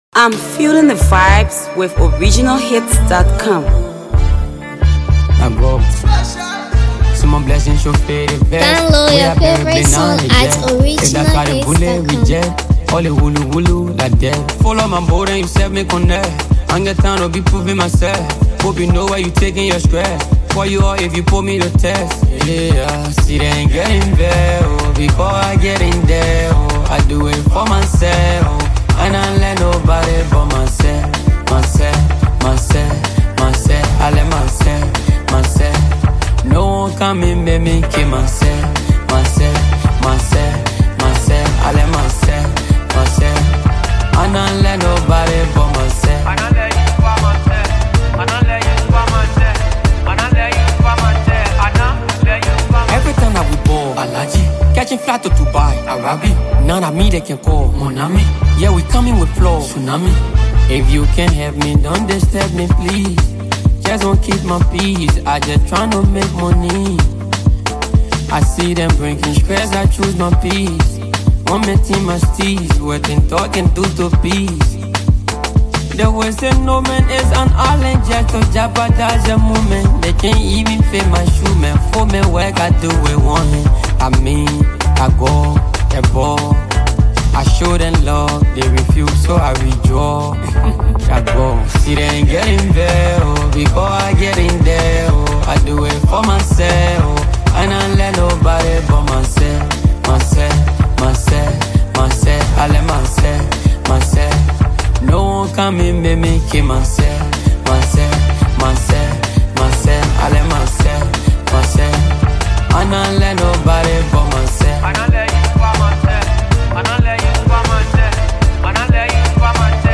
blends smooth vibes with captivating energy